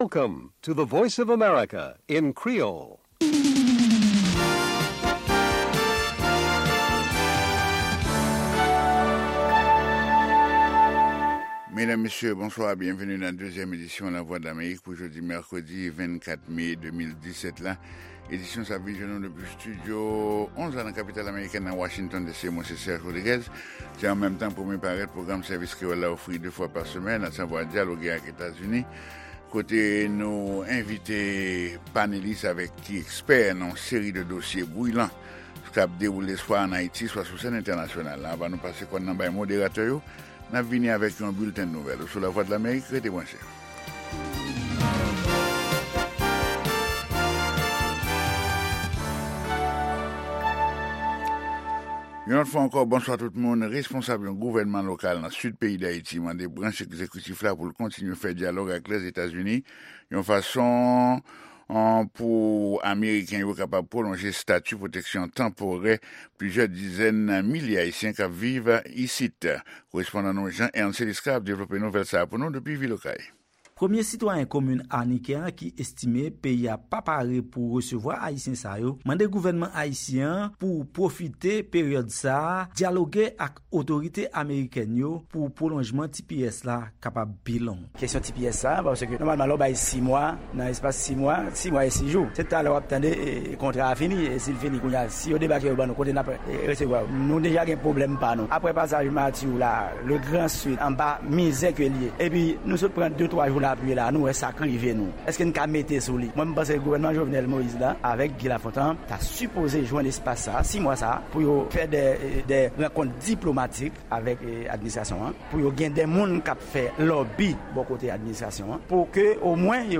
Pwogram mitan jounen an. Dyaloge ak Etazini sou TPS pou Imigran Ayisyen yo. 2 Avoka imigrasyon tap reponn kesyon odotè yo.